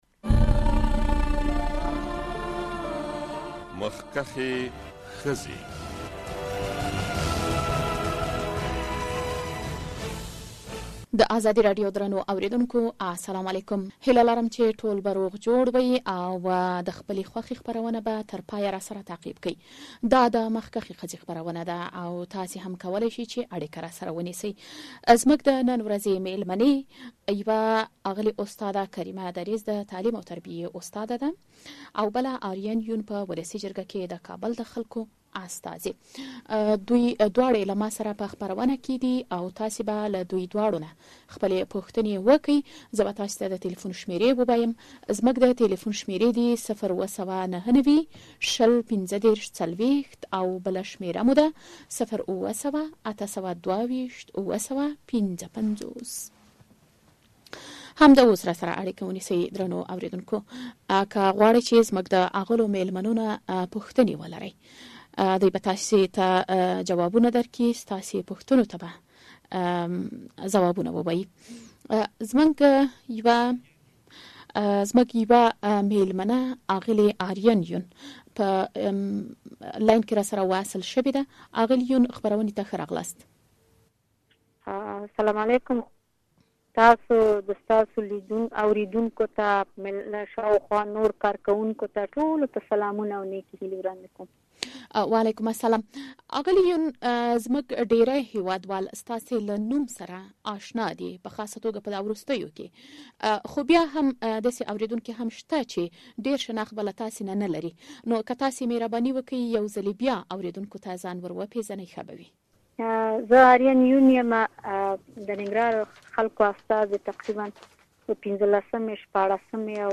د ازادي راډیو اونیزه 'مخکښې ښځې' خپرونه کې هره اوونۍ یوه یا دوه د هېواد پېژندل شوې څېرې غوښتل کیږي چې ستاسو پوښتنو ته ځوابونه ووایي.